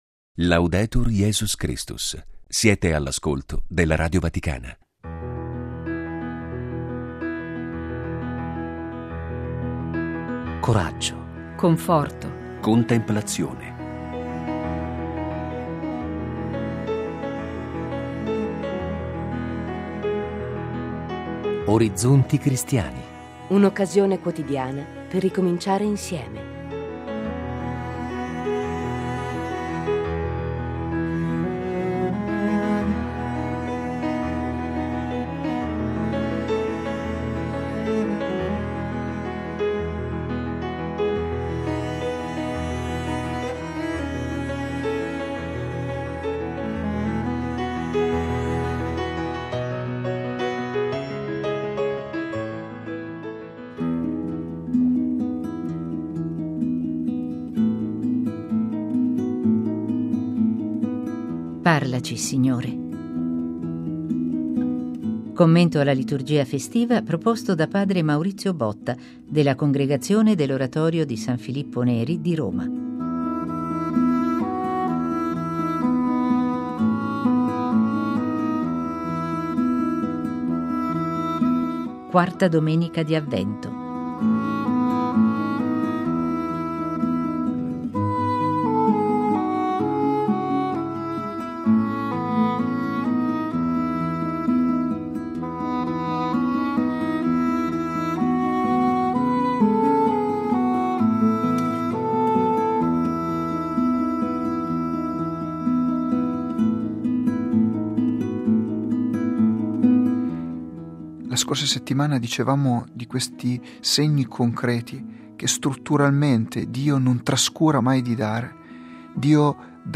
Lo spazio dedicato alla meditazione quotidiana il venerdì ospita il commento alla liturgia festiva